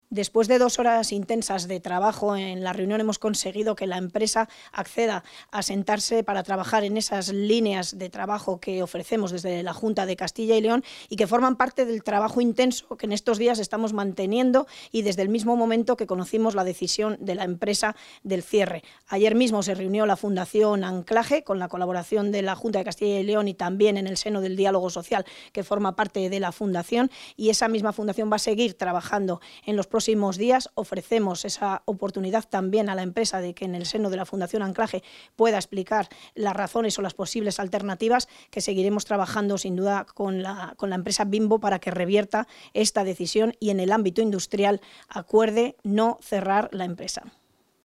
Valoración de la consejera de Agricultura, Ganadería y Desarrollo Rural.